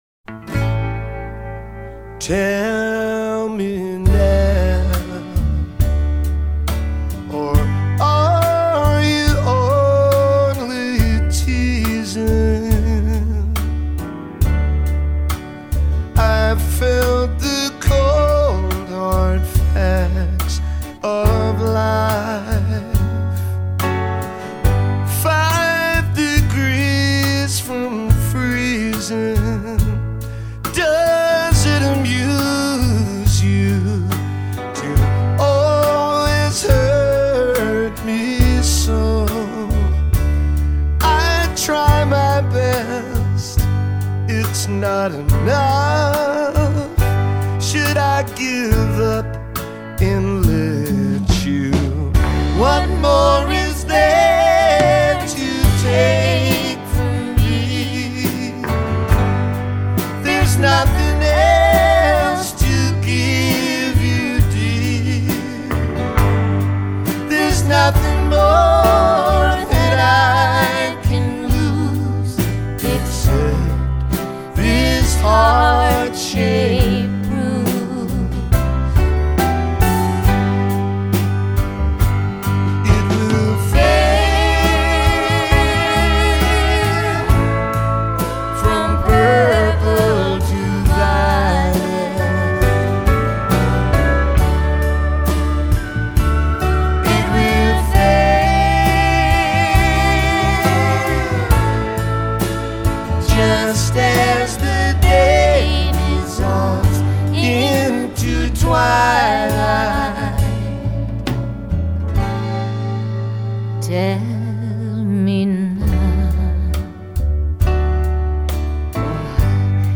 the queen of backing vocals